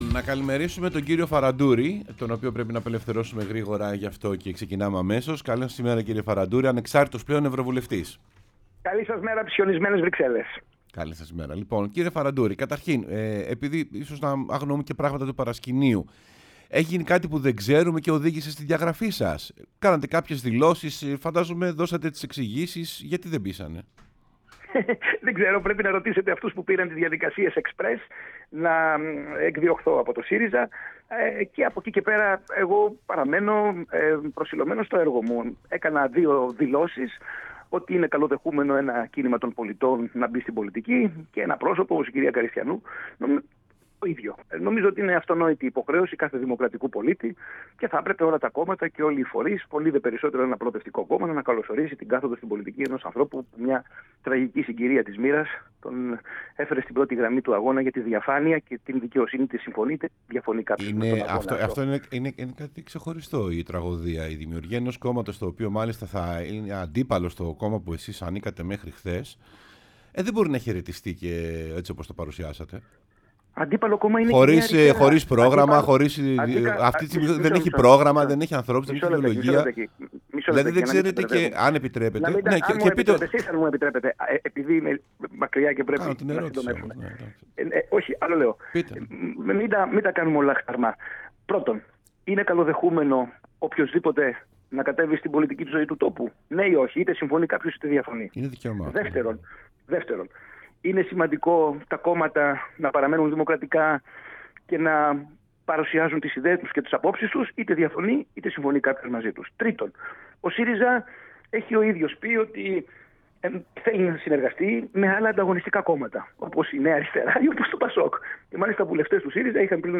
O Νικόλας Φαραντούρης, ευρωβουλευτής ,μίλησε στην εκπομπή Σεμνά και ταπεινά